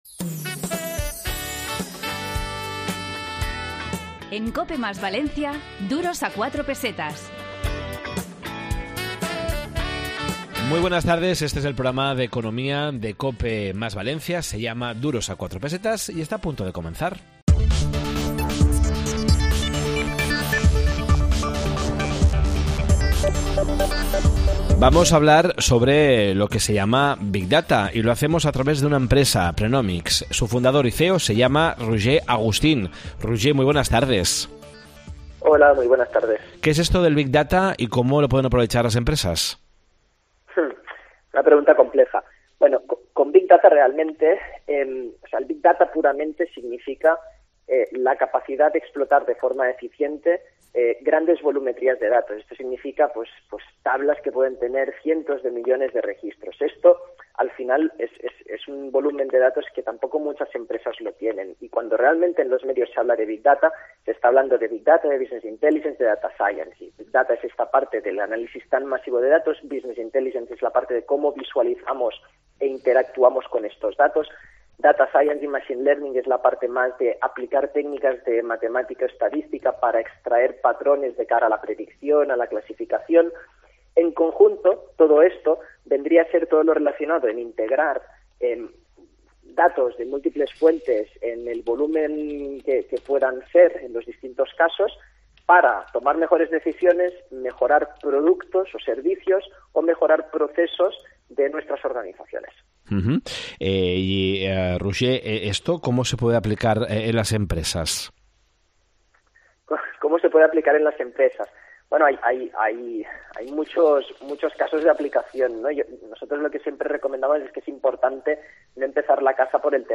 Esta semana en Duros a 4 Pesetas de COPE + Valencia, en el 92.0 de la FM, hemos preparado un programa dedicado a las compañías de telecomunicaciones, la inteligencia de datos y las negligencias médicas.